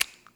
Percs
Scrape.wav